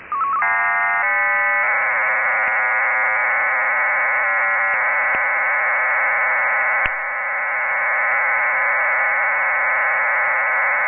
• RDFT is an amateur radio digital mode used to transmit files: